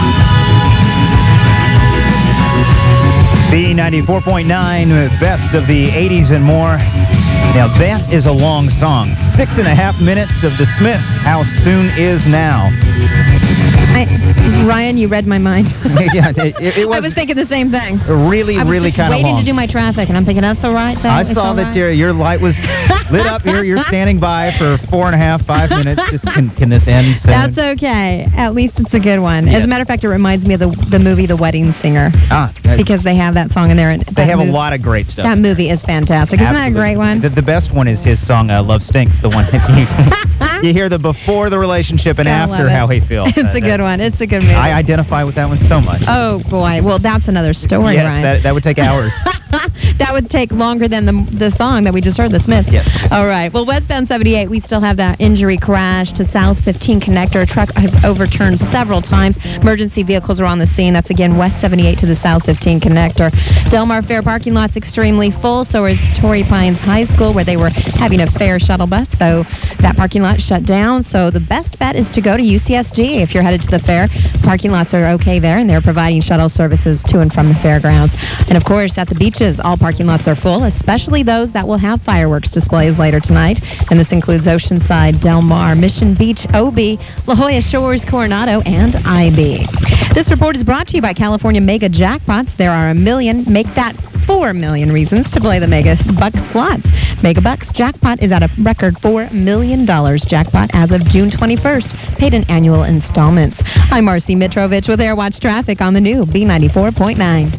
radio stations